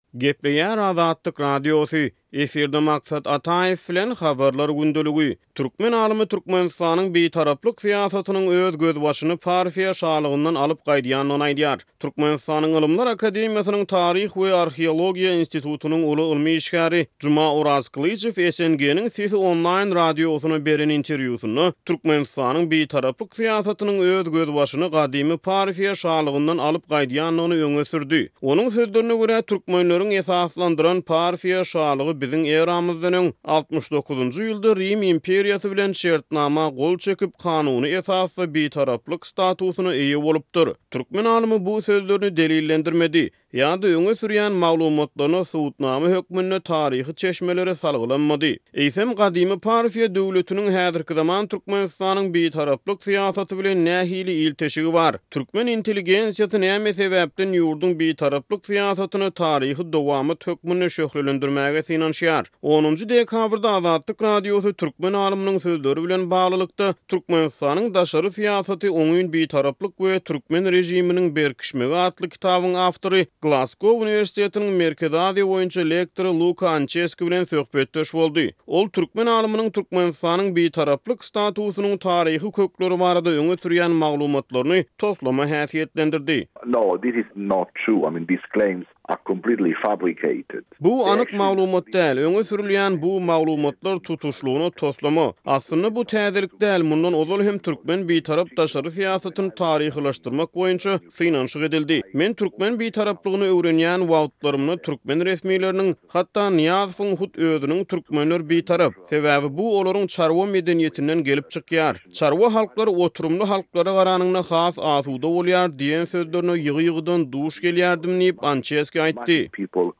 interwýusynda